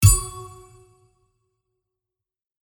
attack_skill.mp3